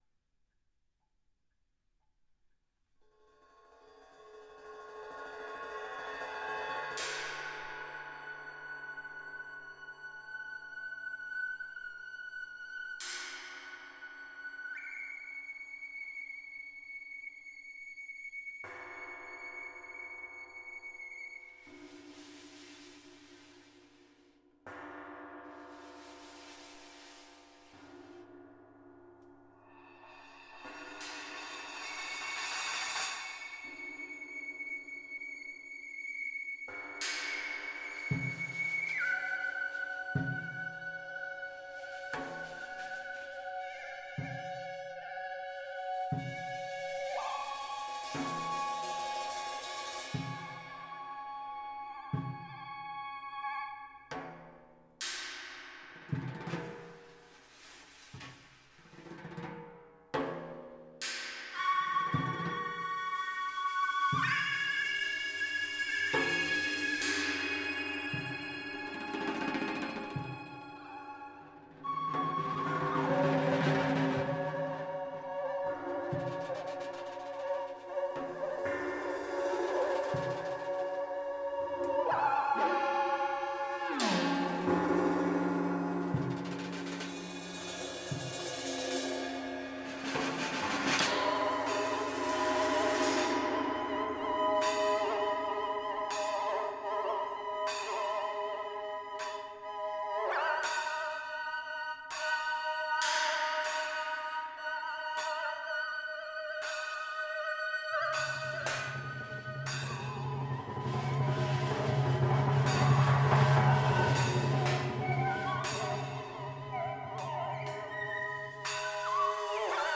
Unraveled, for Percussion Quartet and Impossible Electronic Orchestra, re-imagines a famous melody through the lens of a mathematical tool called Fourier Analysis.
At first, the "electronic orchestra" plays the melody with all of its slow oscillations— and therefore the overall contour—removed. What is left is only the fluttering of the melody’s small-scale ornamentation.
Finally, at the end, it is the the small-scale ornamentation that is removed, causing the melody to devolve into sweeping glissandi.
First, they use rolls to reinforce different oscillations found within the melody.